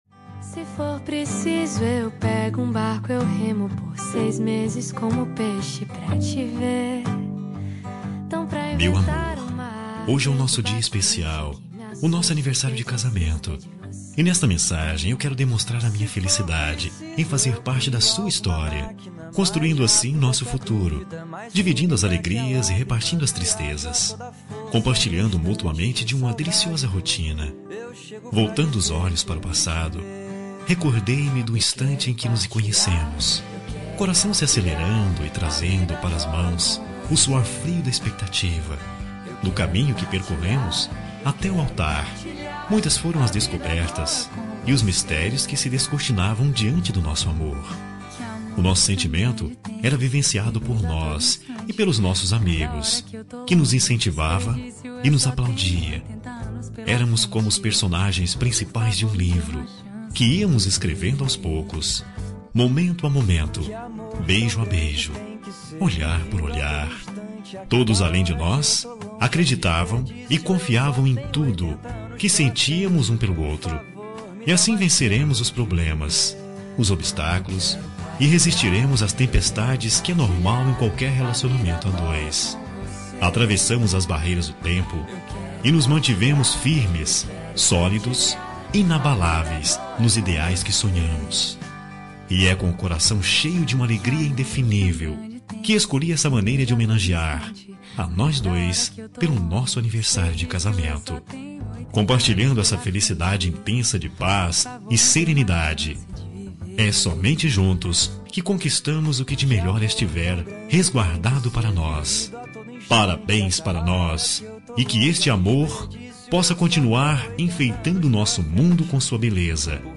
Telemensagem de Aniversário de Casamento Romântico – Voz Masculina – Cód: 5011